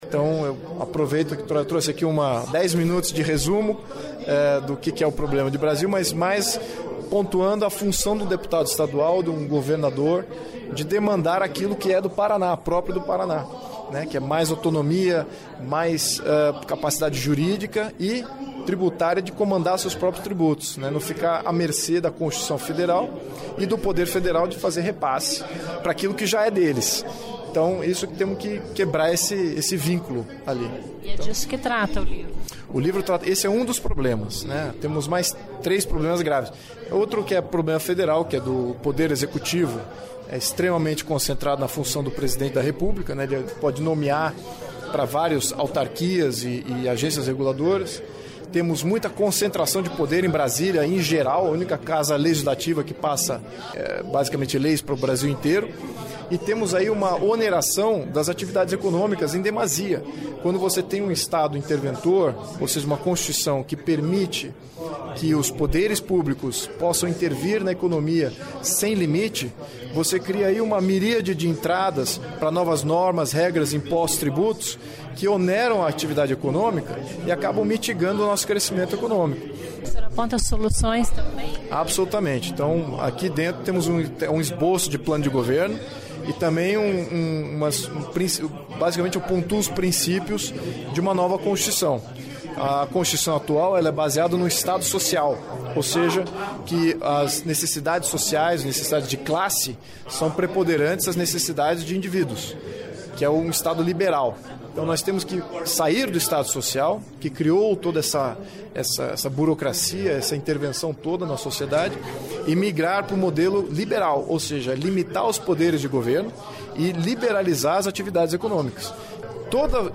O descendente da família real,   Luiz Phillipe de Orleans e Bragança, foi o convidado do Grande Expediente da sessão Plenária desta segunda-feira (13) com o  lançamento do  livro "Por que o Brasil é um país atrasado?", onde fala de um novo modelo de distribuição de recursos a partir de uma mudança na Constituição. Ouça a entrevista.